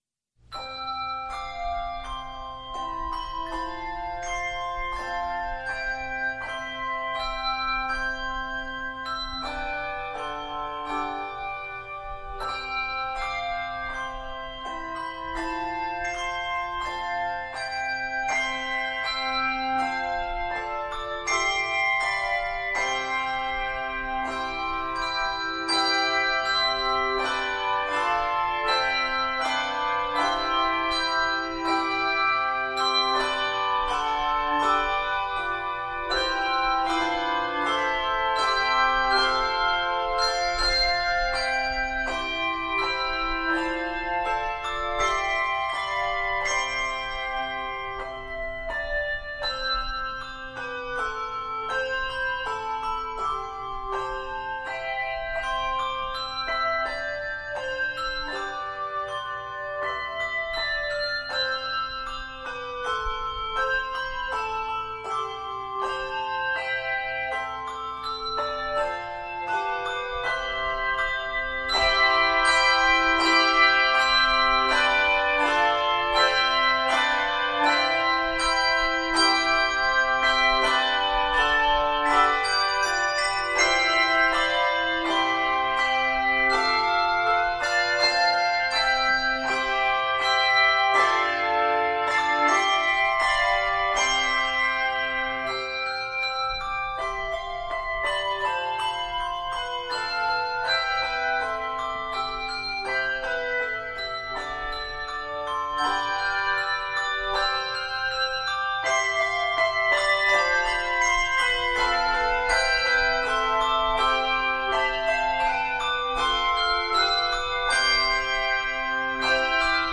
fresh and elegant setting